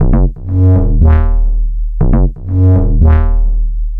TSNRG2 Bassline 020.wav